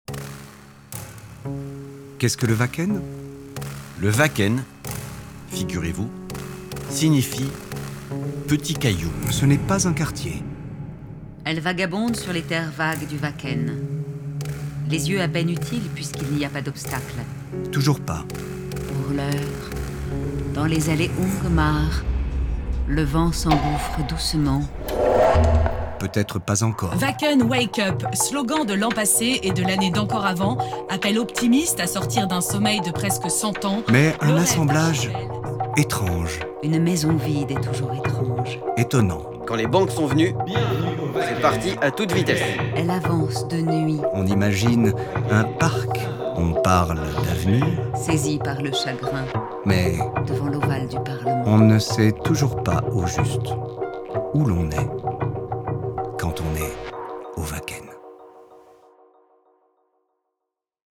MUTATION Une fiction sonore également disponible en version allemande - Die Flüsse zähmen ! Eine geolokalisierte Hörfiktion, die auch in der deutschen Version (Die Flüsse zähmen) verfügbar ist!